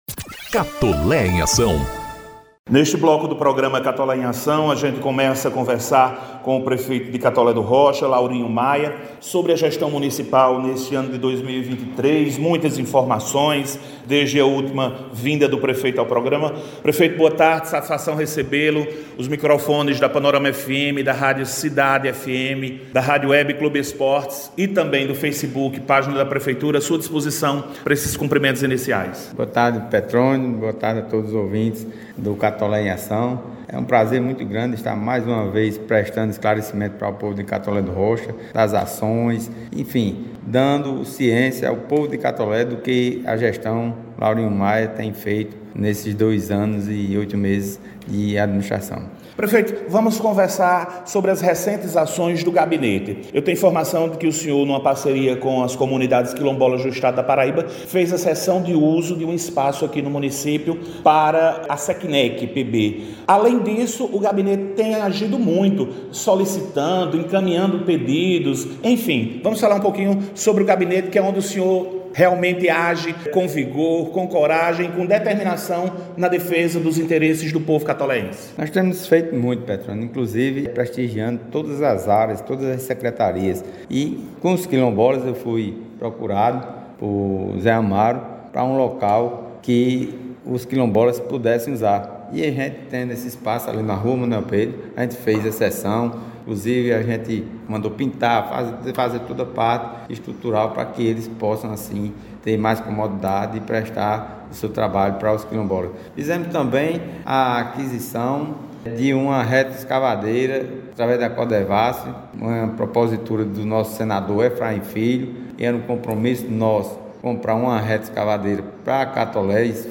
Prefeito Laurinho Maia destacou no programa de sexta-feira(25) as ações, serviços, obras e a parceria com o Governador João Azevêdo.
Durante entrevista, o prefeito catoleense falou da importância da parceria com o Governo da Paraíba que tem possibilitado a pavimentação asfáltica (Travessias Urbanas), a construção do Condomínio “Cidade Madura”, a distribuição de alevinos (Peixamento de Açudes), a aquisição de Ensiladeira (SEDAP) e a construção da Creche (Tancredo Neves), entre inúmeras outras ações.